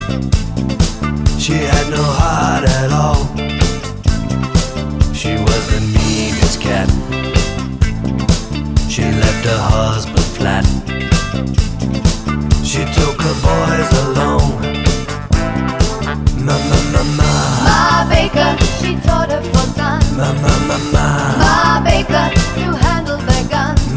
Duet Disco 4:38 Buy £1.50